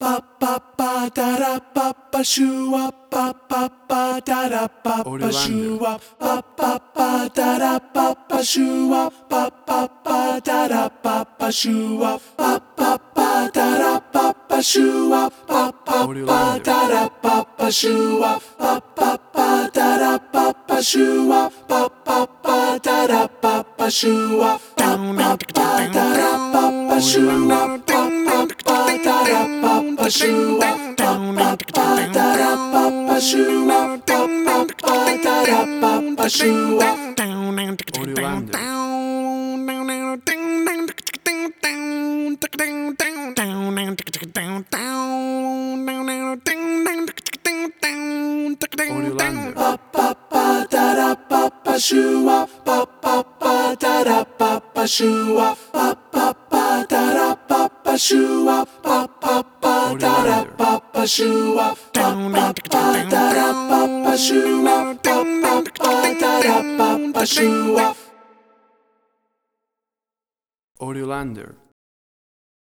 WAV Sample Rate: 16-Bit stereo, 44.1 kHz
Tempo (BPM): 156